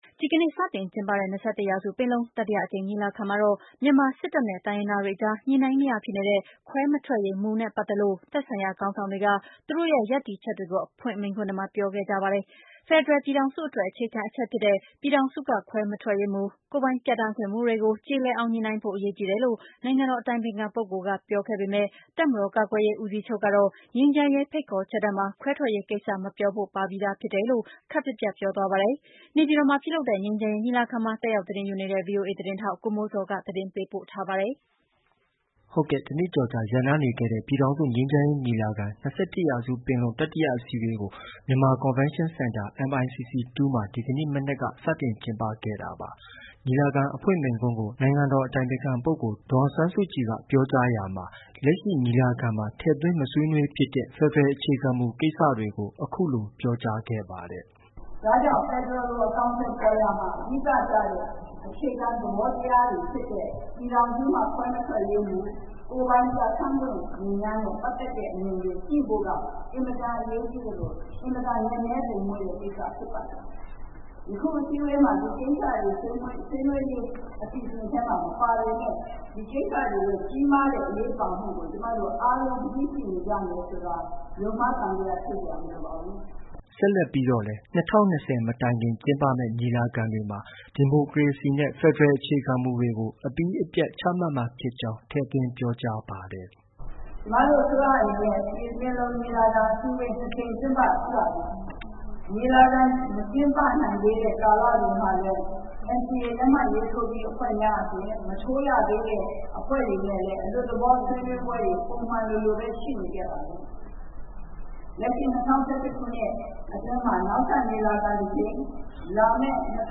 တနှစ်ကျော်ကြာရပ်နားနေခဲ့တဲ့ ပြည်ထောင်စုငြိမ်းချမ်းရေးညီလာခံ- ၂၁ ရာစုပင်လုံ တတိယအစည်းအဝေးကို မြန်မာကွန်ဗင်းရှင်းစင်တာ MICC 2 မှာ ဒီမနက်က စတင်ကျင်းပခဲ့တာပါ။
ညီလာခံအဖွင့်မိန့်ခွန်းကို နိုင်ငံတော်အတိုင်ပင်ခံပုဂ္ဂိုလ်ဒေါ်အောင်ဆန်းစုကြည်က အဖွင့်အမှာစကားပြောကြားရာမှာ လက်ရှိ ညီလာခံမှာ ထည့်သွင်းမဆွေးနွေးဖြစ်တဲ့ ဖက်ဒရယ်အခြေခံမူကိစ္စတွေကို အခုလို ပြောကြားခဲ့ပါတယ်။
တပ်မတော်ကာကွယ်ရေးဦးစီးချုပ်ဗိုလ်ချုပ်မှုးကြီးမင်းအောင်လှိုင်ကလည်း ခွဲထွက်ရေးကိစ္စရပ်နဲ့ပတ်သက်ပြီးသူရဲ့ နုတ်ဆက်အမှာစကားမှာ အခုလို ထည့်သွင်း ပြောဆိုပါတယ်။